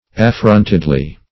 affrontedly - definition of affrontedly - synonyms, pronunciation, spelling from Free Dictionary Search Result for " affrontedly" : The Collaborative International Dictionary of English v.0.48: Affrontedly \Af*front"ed*ly\, adv.